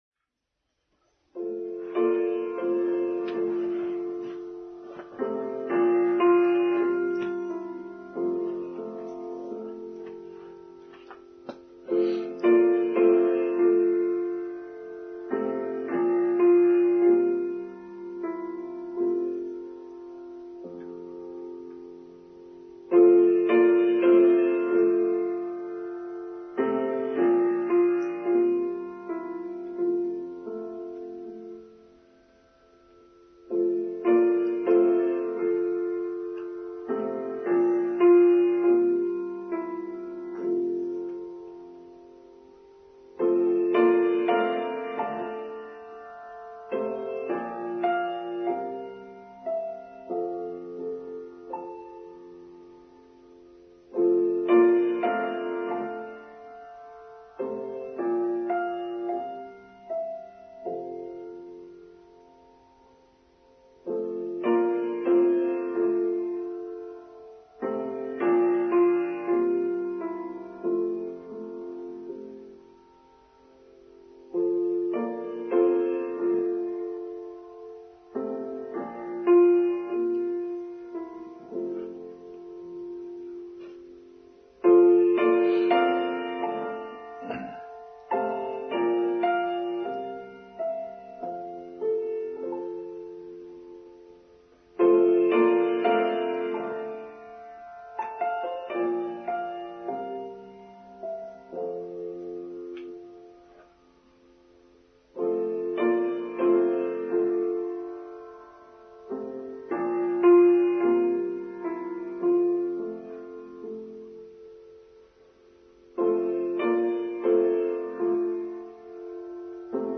Be Gentle: Online service for Sunday 18th July 2021